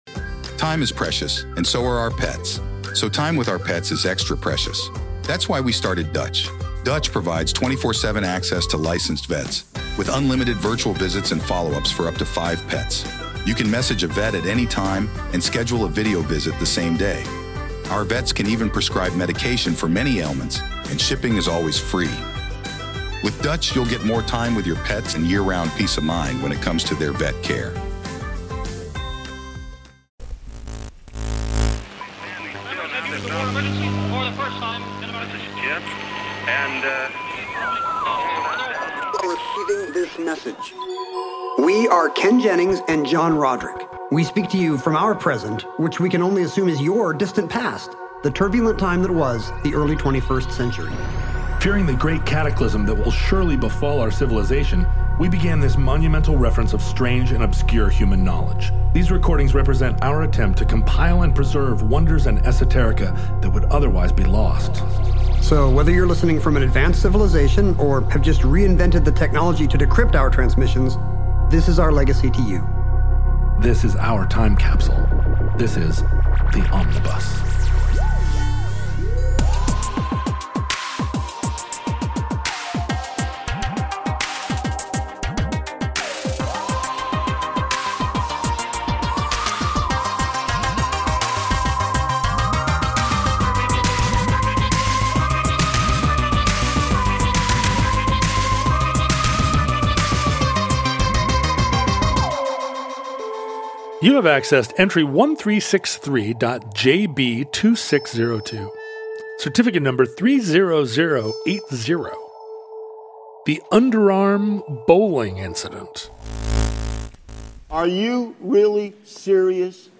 In which an Australian cricket team creates an international controversy with an unorthodox but legal strategy, and Ken accidentally does a Boston accent.